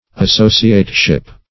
\As*so"ci*ate*ship\